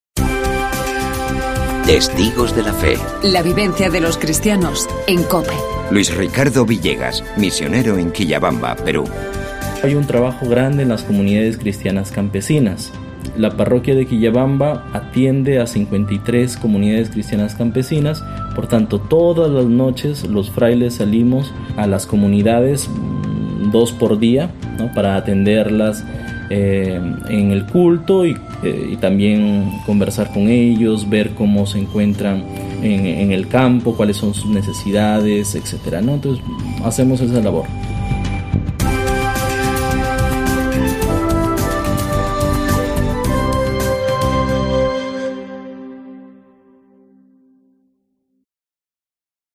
AUDIO: Escuchamos el teestimonio